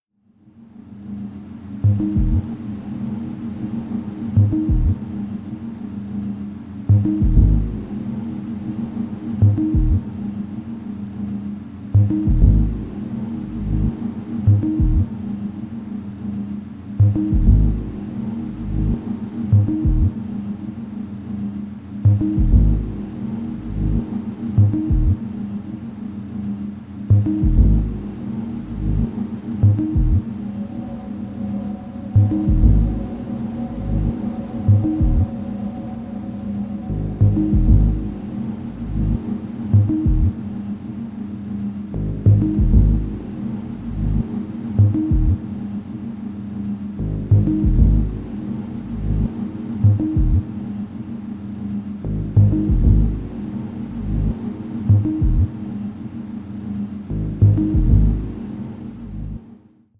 é uma orquestração de música ambiente